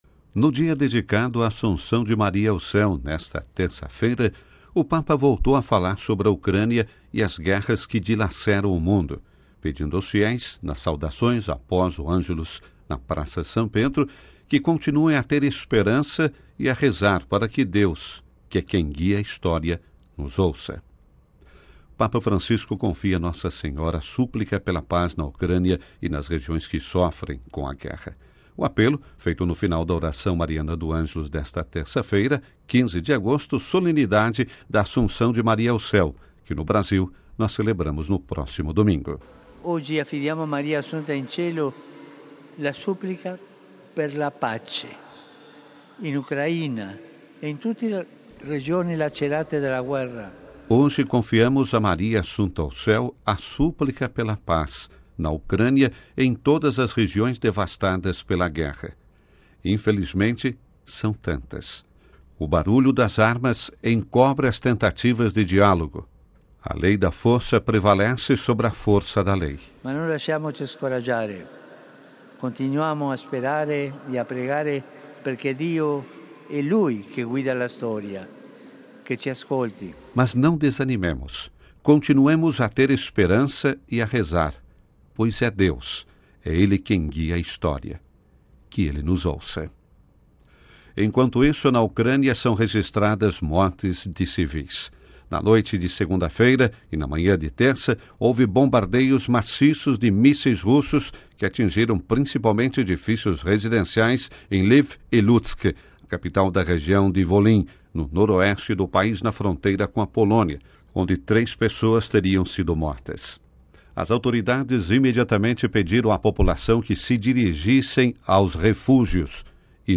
O Papa Francisco confia a Nossa Senhora a súplica pela paz na Ucrânia e nas regiões que sofrem com a guerra. O apelo foi feito ao final da oração mariana do Angelus nesta terça-feira, 15 de agosto e de Solenidade da Assunção de Maria ao Céu: